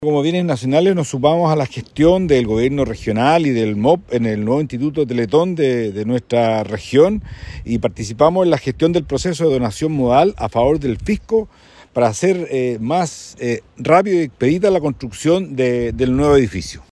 A su vez, Eduardo León, seremi de Bienes Nacionales, precisó que desde la cartera agilizarán el proceso de realización de las obras.